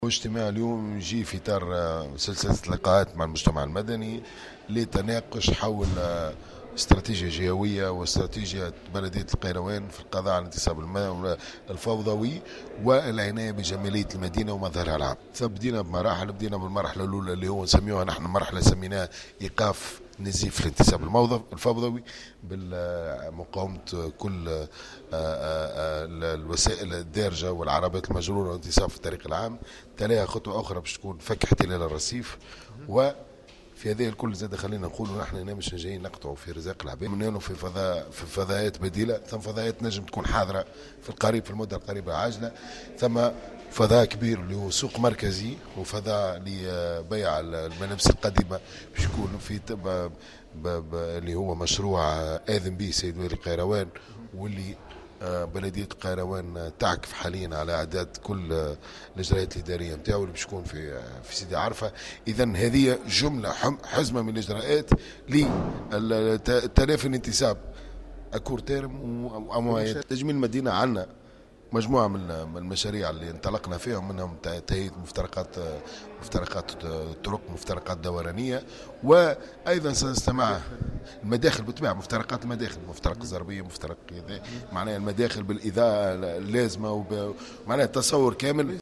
انطلقت منذ أيام بمدينة القيروان حملة لمقاومة الانتصاب الفوضوي و الاستحواذ على الأرصفة، وفق ما أكده رئيس النيابة الخصوصية لبلدية القيروان، بسام الشريقي في تصريح لمراسل "الجوهرة أف أم".